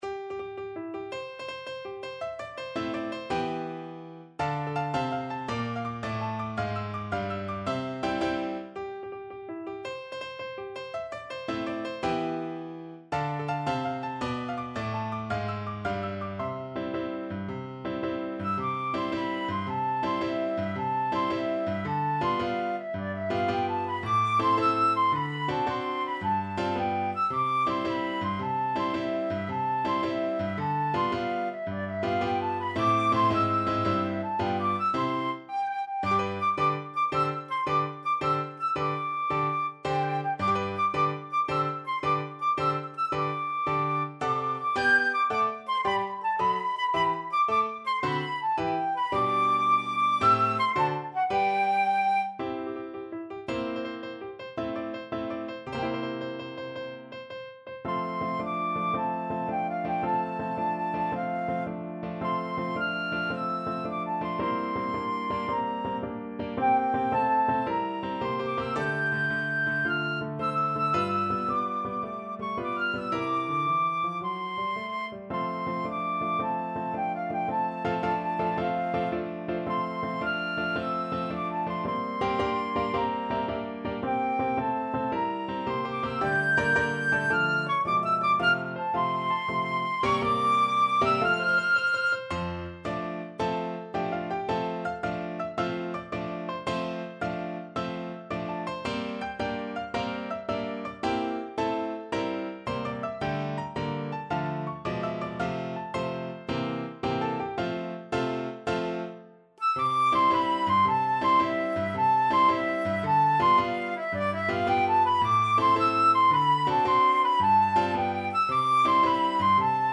6/8 (View more 6/8 Music)
March .=c.110
Classical (View more Classical Flute Music)